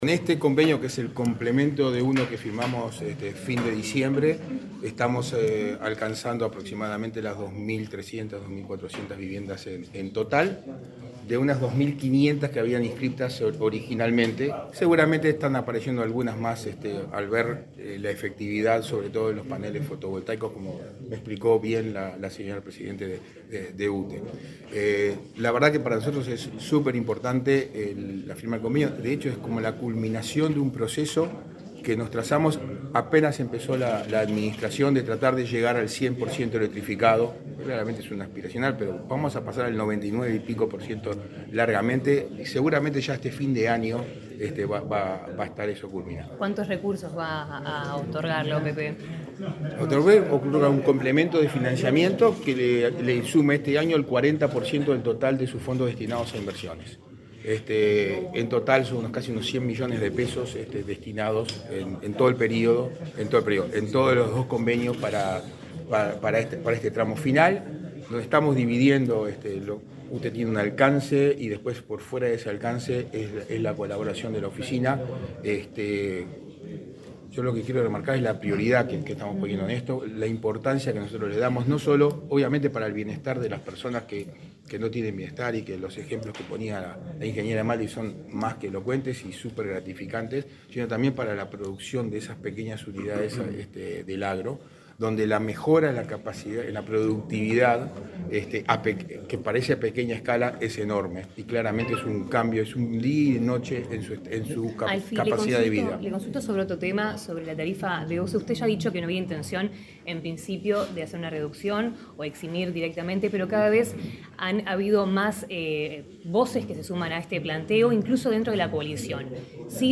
Declaraciones de autoridades en convenio entre OPP y UTE
Declaraciones de autoridades en convenio entre OPP y UTE 07/07/2023 Compartir Facebook X Copiar enlace WhatsApp LinkedIn El director de la Oficina de Planeamiento y Presupuesto (OPP), Isaac Alfie, y la presidenta de la UTE, Silvia Emaldi, dialogaron con la prensa acerca de la trasferencia de fondos para electrificación rural.